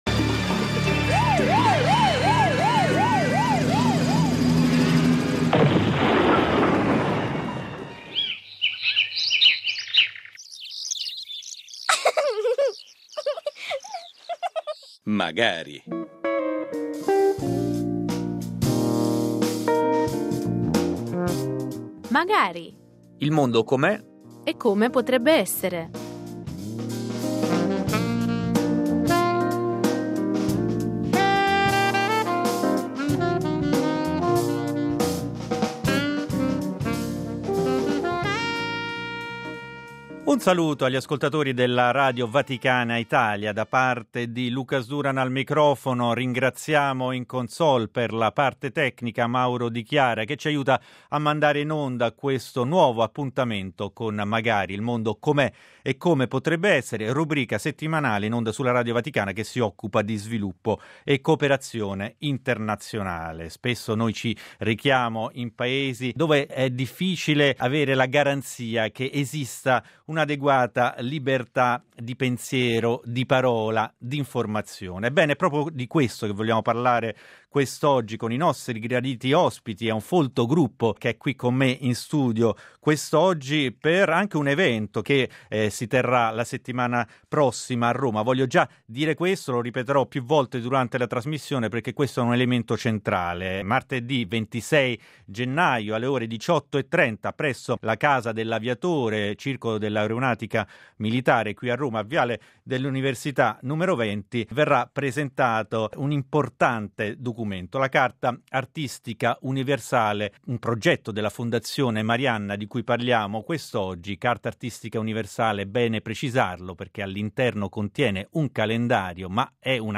Ne parlano ai nostri microfoni